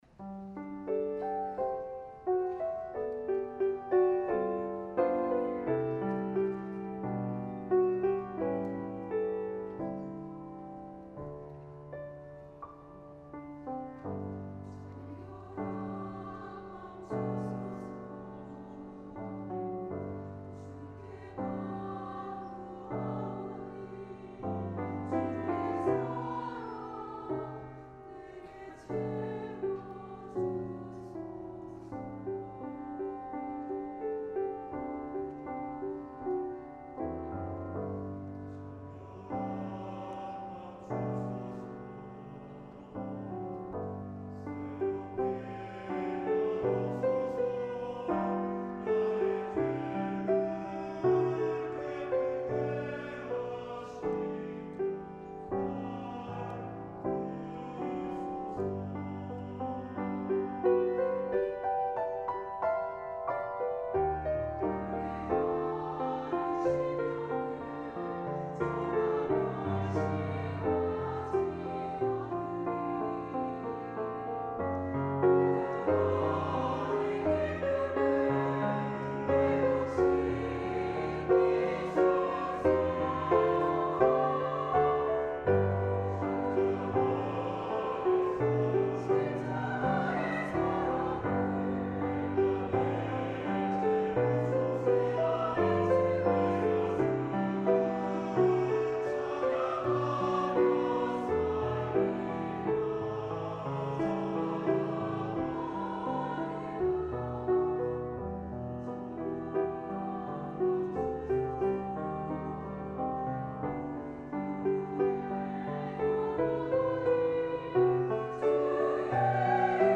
찬양 :: 정결한 마음 주소서